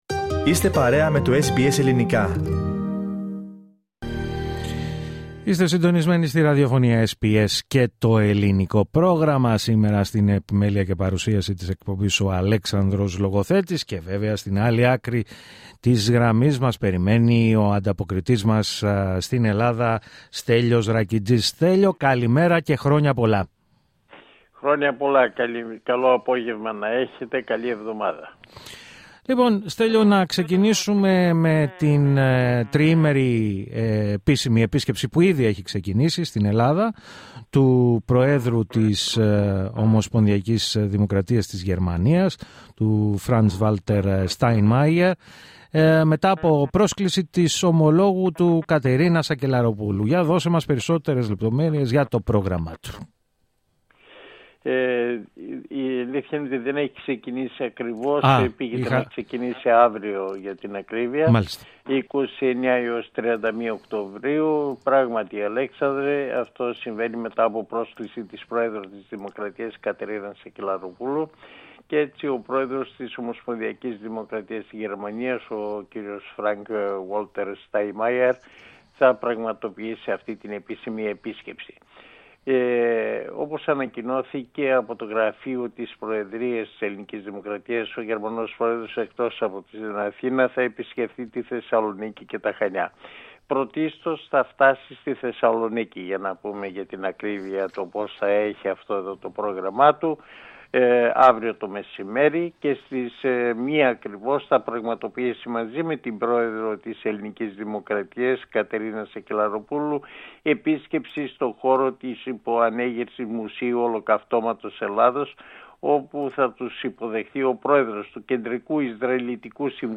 Ακούστε την εβδομαδιαία ανταπόκριση από την Ελλάδα